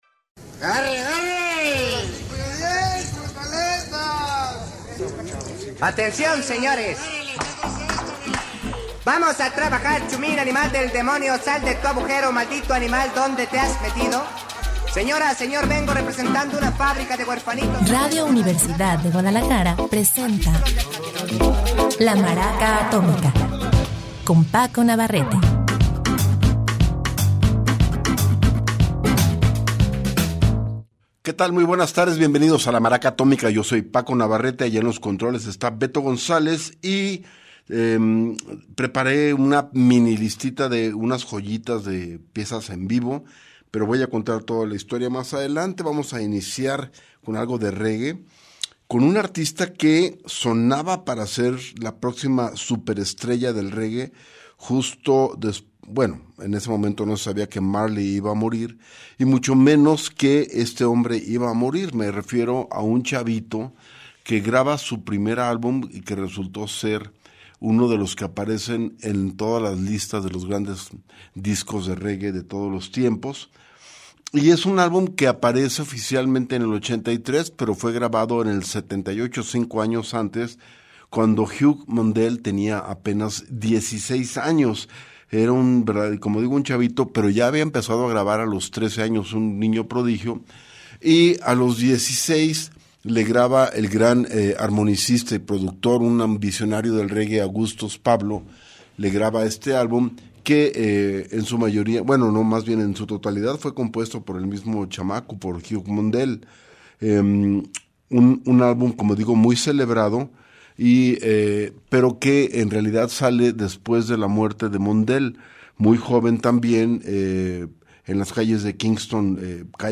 tenemos una mini lista con joyitas de piezas en vivo